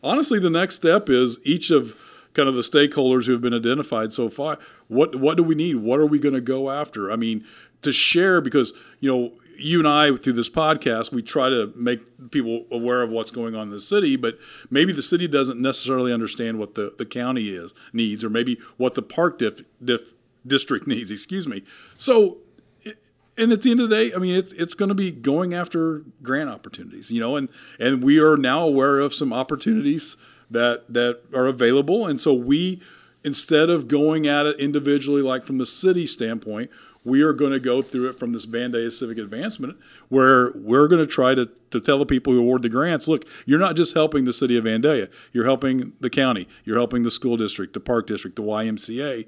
Speaking on our podcast “Talking about Vandalia,” Mayor Doug Knebel says now they will get down to work with their next meeting on Tuesday and he talks more about what some of their work might look like.